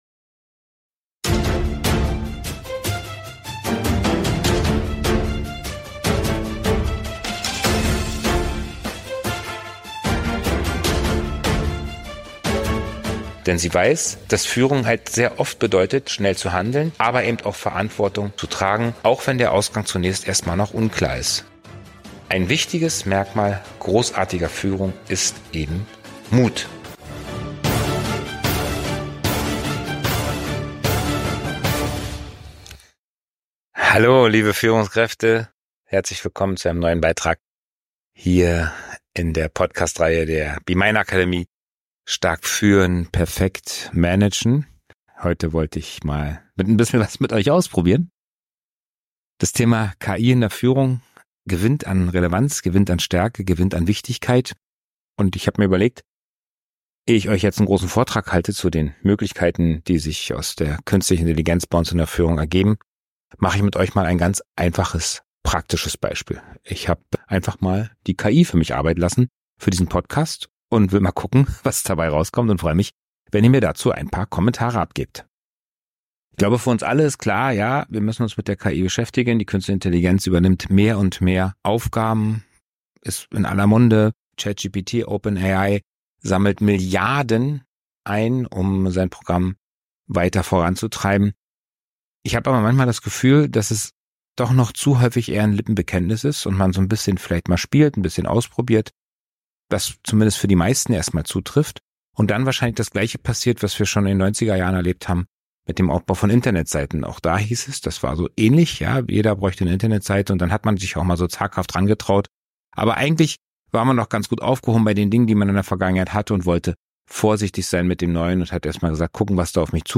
17. Die großartige Führungskraft - KI generierter Inhalt. ~ Stark führen - perfekt managen. Podcast